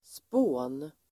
Uttal: [spå:n]